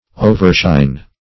Search Result for " overshine" : The Collaborative International Dictionary of English v.0.48: Overshine \O`ver*shine"\, v. t. 1.